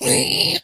zpighurt1.ogg